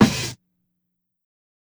SNARE_RIGHTPLACE.wav